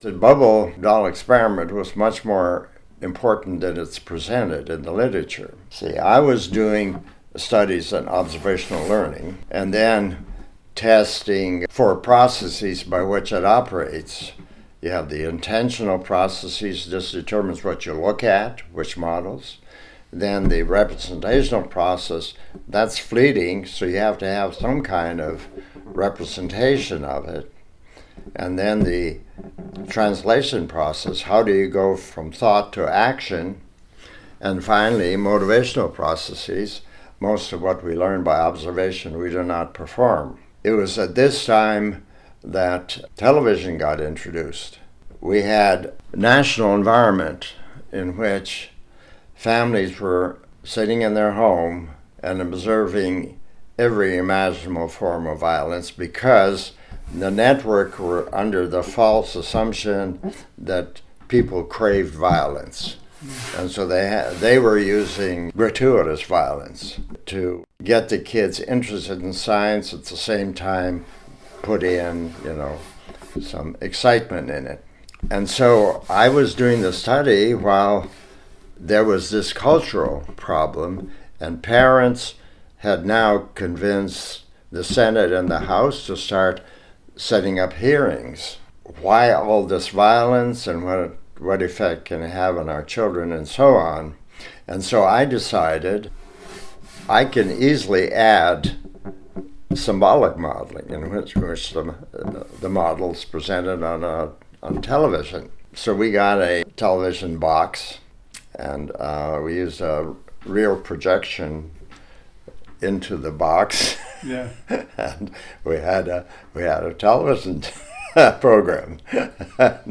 And so, it is with great pleasure that I share with you some tidbits, excerpts, and reflections from my conversations with Dr. Albert Bandura, the David Jordan Professor Emeritus of Social Science in Psychology at Stanford University.
Dr. Bandura recalls the Bobo doll experiments in this excerpt: